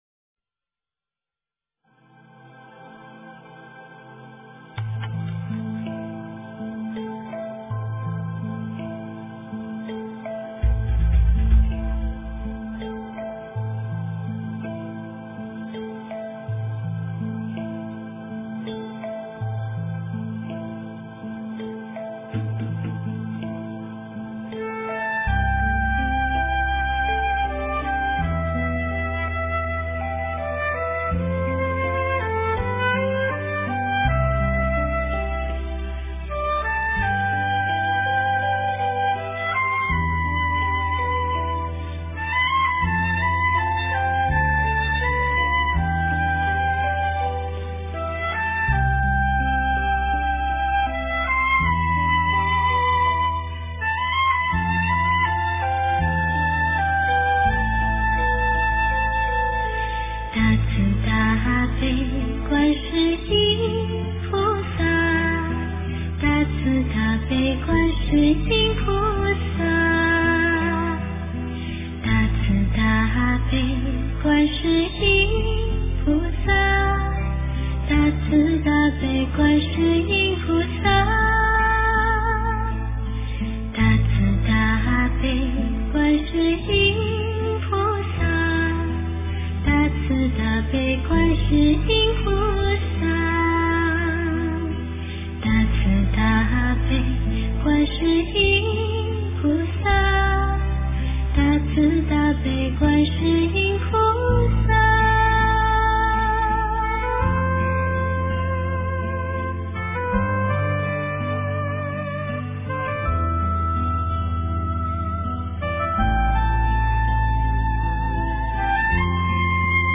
大慈大悲--佛教歌曲
大慈大悲--佛教歌曲 冥想 大慈大悲--佛教歌曲 点我： 标签: 佛音 冥想 佛教音乐 返回列表 上一篇： 千年之悦--风潮唱片 下一篇： 莲花--巫娜 相关文章 07.